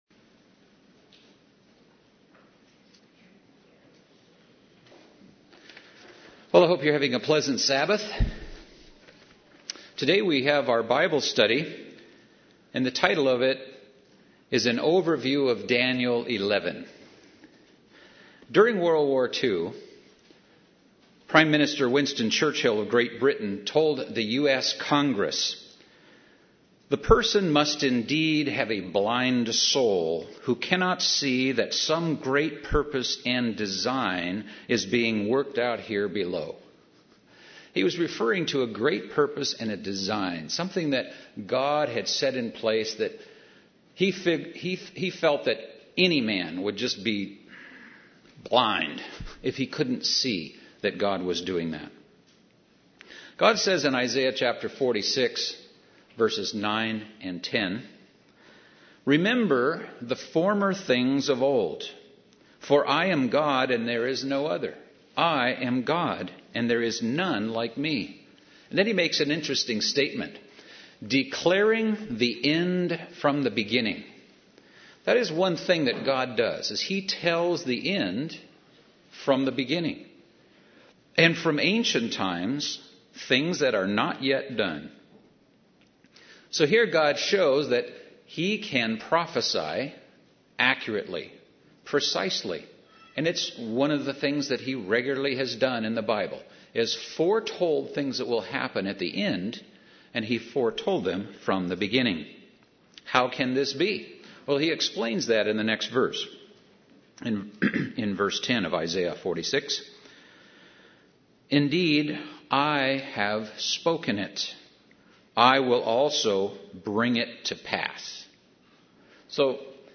Bible study - God foretells what will happen from the beginning, letting us see how He will sometimes steer events. Let's look at how God does this by looking at Daniel 11.